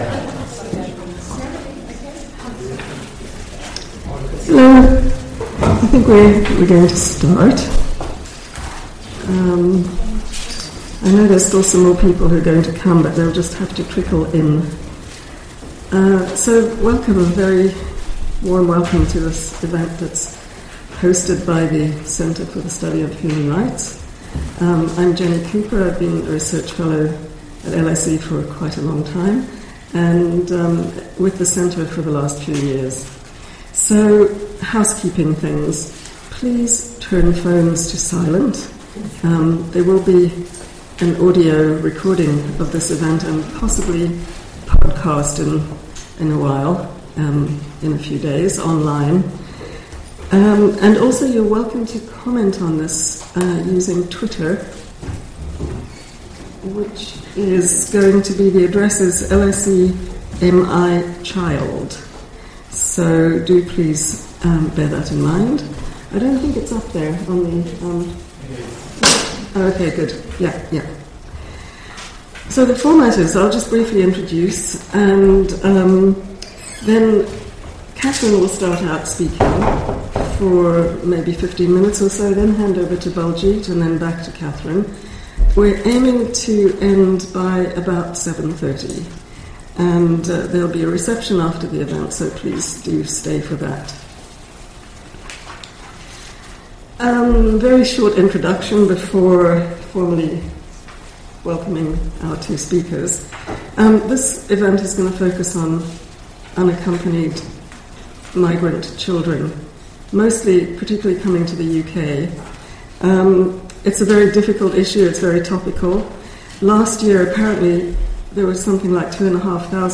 Hosted with the Department of Sociology, 22nd May 2019